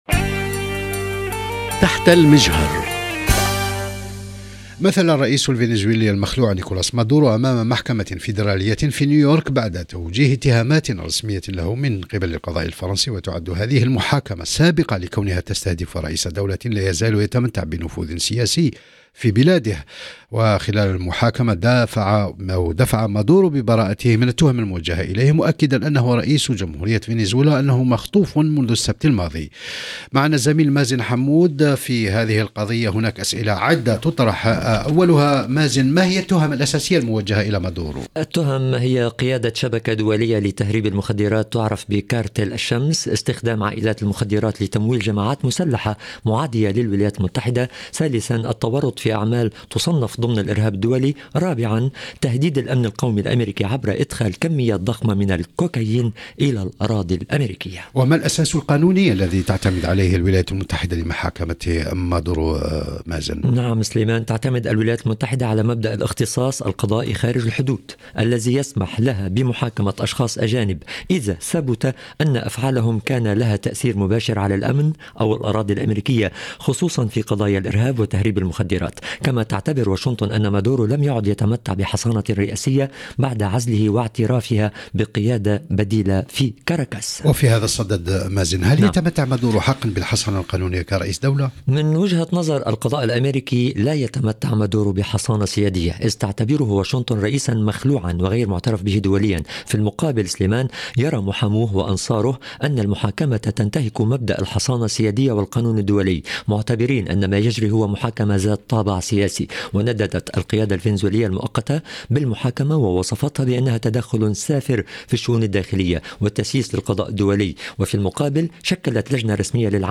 في هذا السياق، يتناقش الزميلان